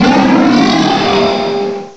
cry_not_solgaleo.aif